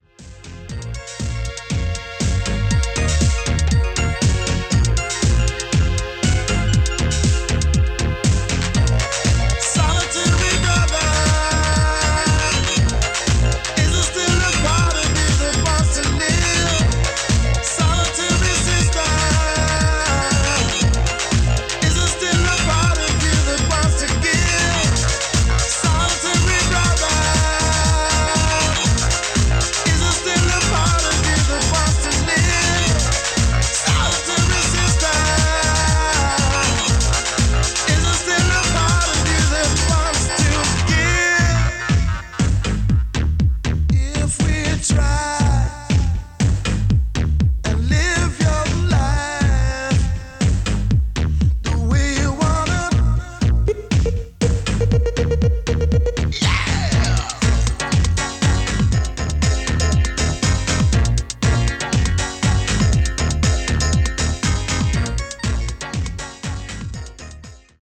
Below is a test recording made with the GXC-706D and played back by it on a normal position tape:
Akai-GXC-706D-Test-Recording.mp3